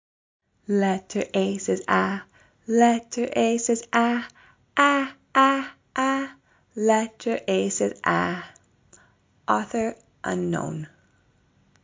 Singing
Tune: Farmer in the Dell